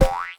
reward_drop_03.ogg